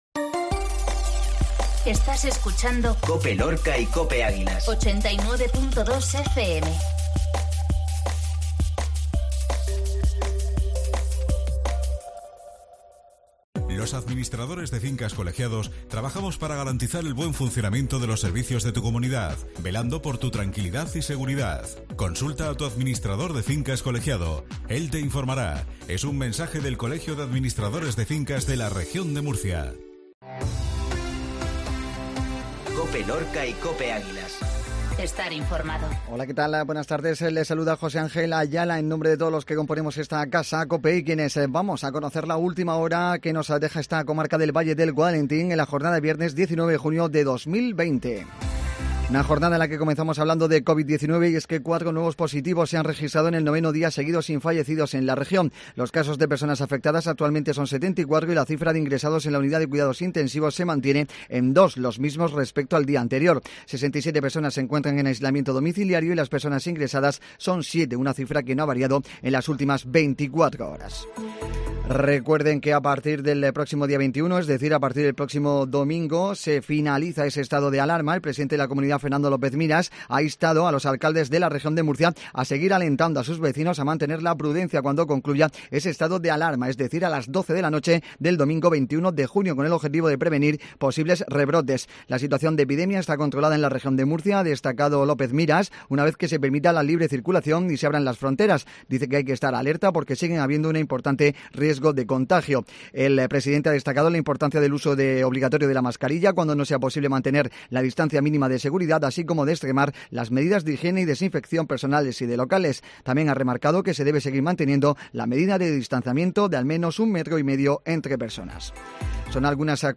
INFORMATIVO MEDIODIA COPE LORCA VIERNES